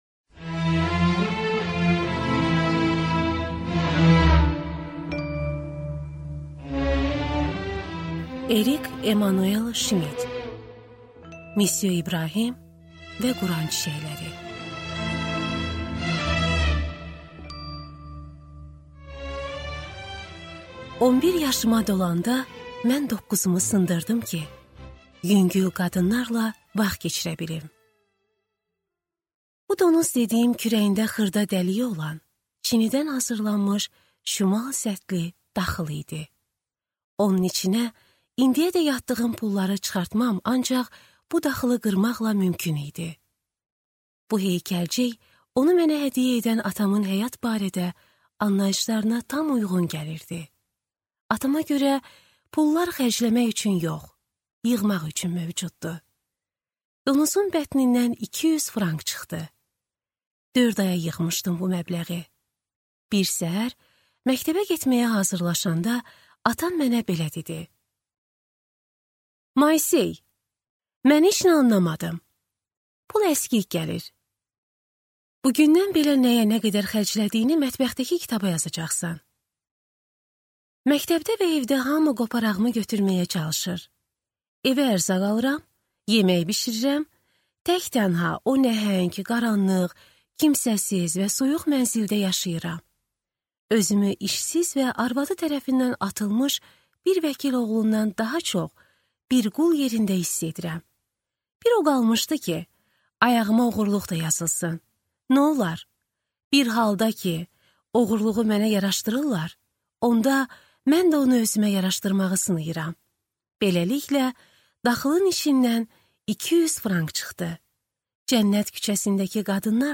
Аудиокнига Müsyo İbrahim və Quran çiçəkləri | Библиотека аудиокниг